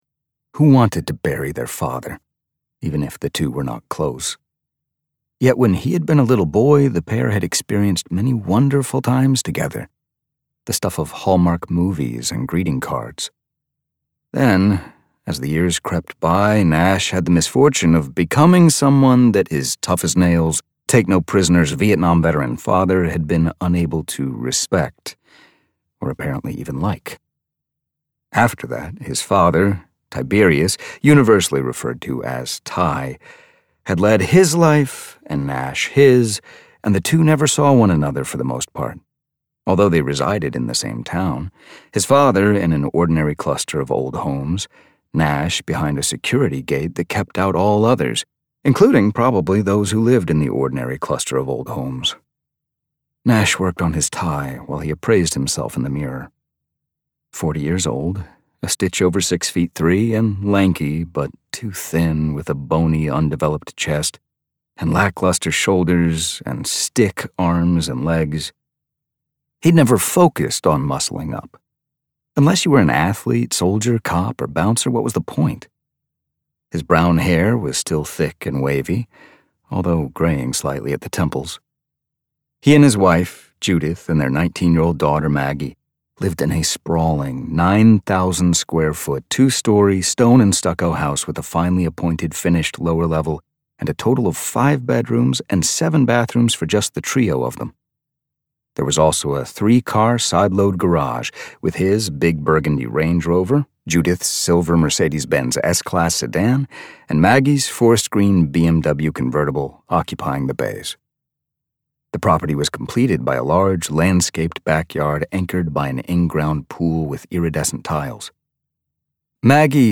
1. Audiobook Download (Unabridged) $31.99